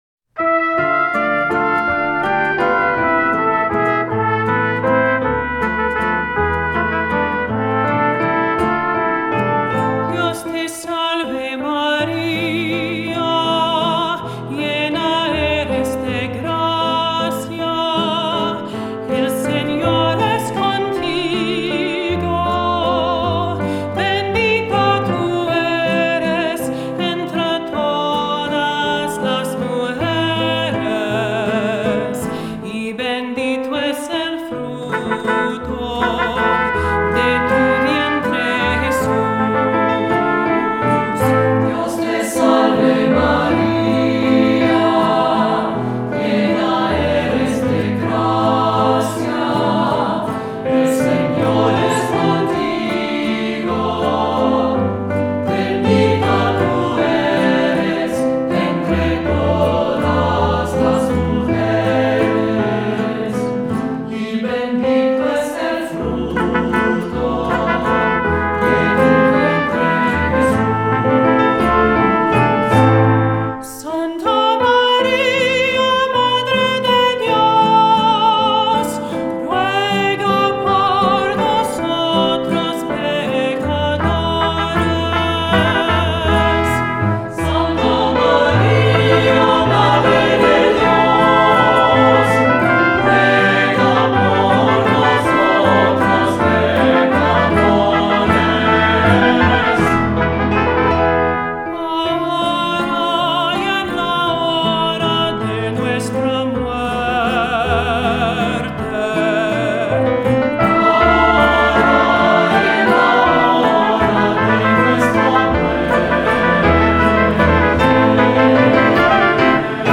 Voicing: SAT; cantor; assembly